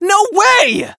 hotshot_hurt_07.wav